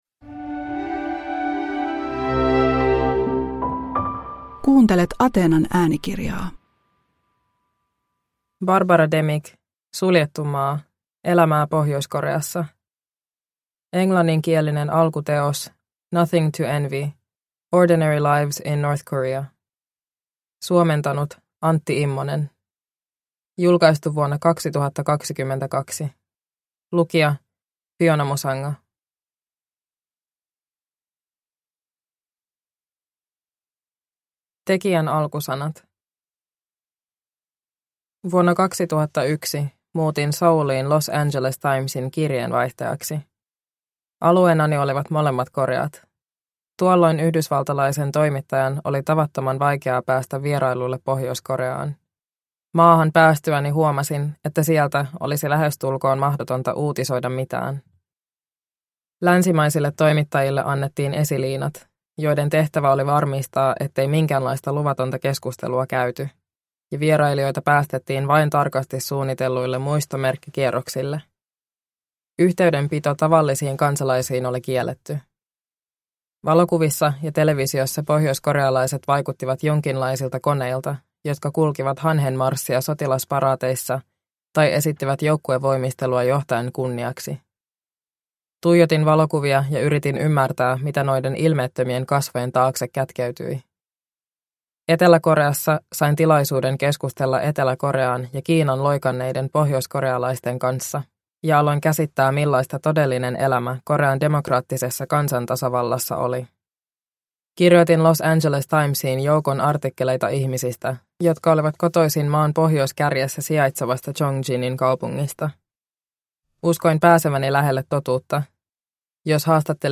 Suljettu maa – Ljudbok – Laddas ner